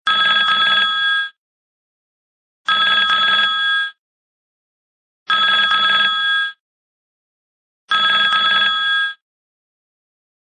Android, Klassisk Telefon, Klassisk